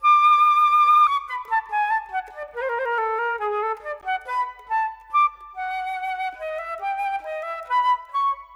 Modern 26 Flute 01.wav